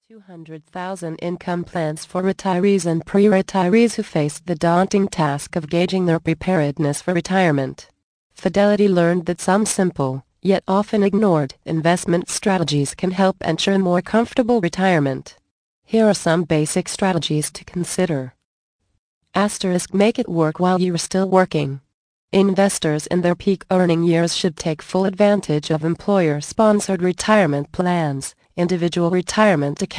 Easy Retirement Planning Tips Audio Book. Vol. 7 of 8